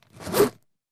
Index of /server/sound/clothing_system/fastener